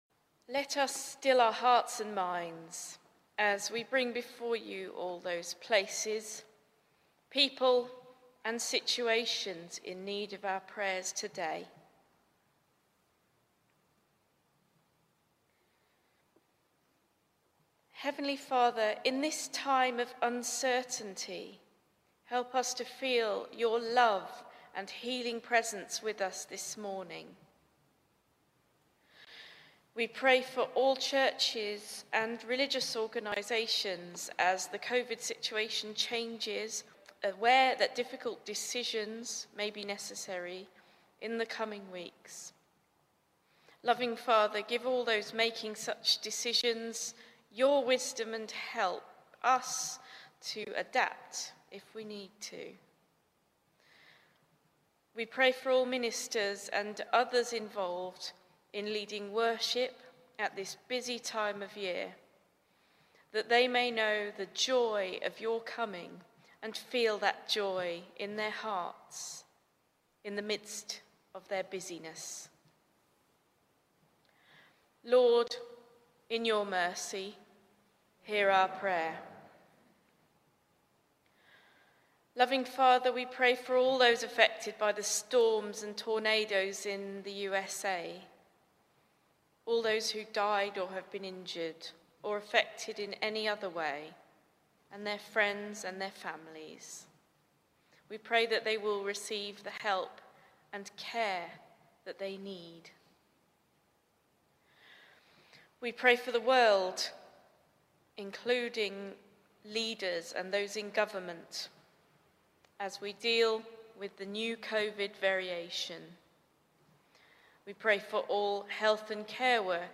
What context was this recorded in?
I prayed these prayers during a morning service and have removed the prayers for members of the Church community to protect their privacy.